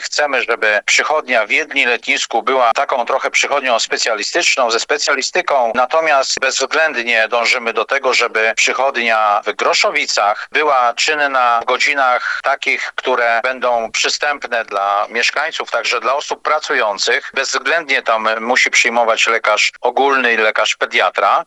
W zamian za to, chcemy zaoferować naszym mieszkańcom i pacjentom lekarzy specjalistów, dodaje burmistrz Jedlni-Letniska: